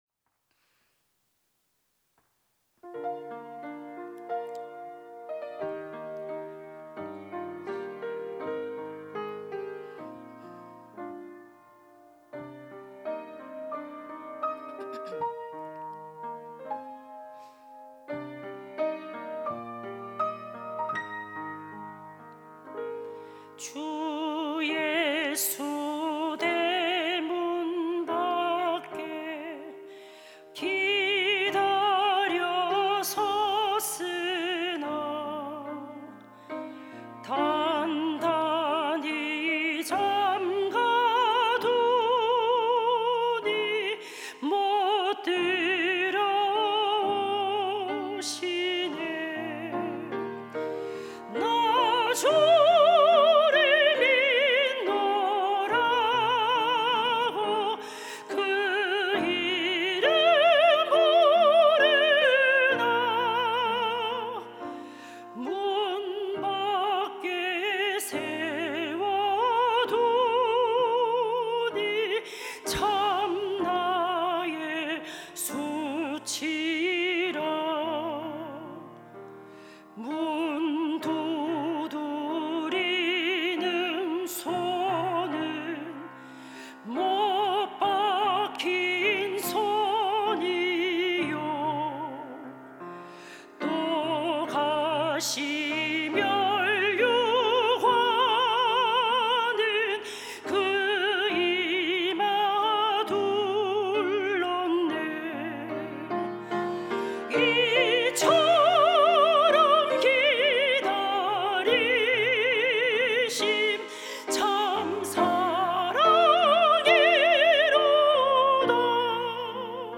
특송과 특주 - 주 예수 대문 밖에